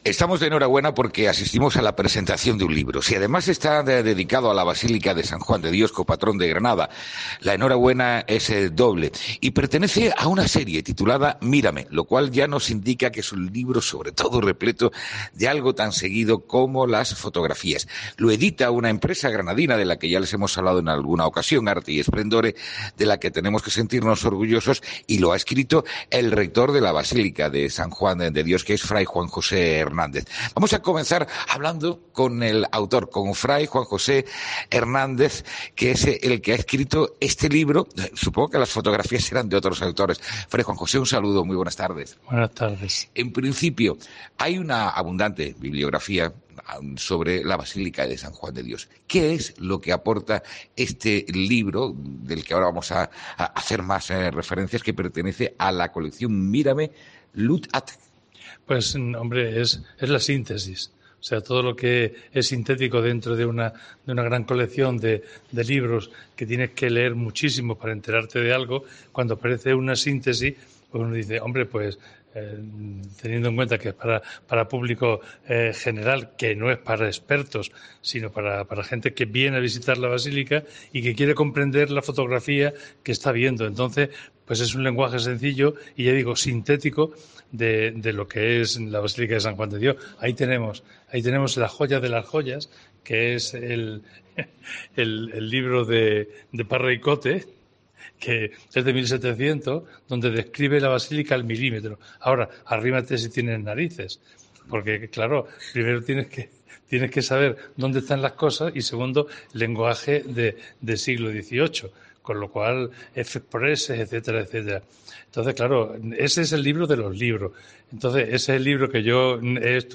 han comentado durante una entrevista en COPE las característica de este libro.